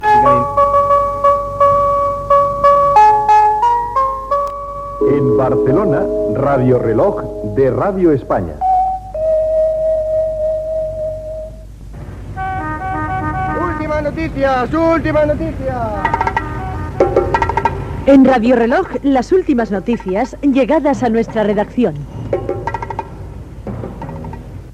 Indicatiu de l'emissora i careta de les notícies.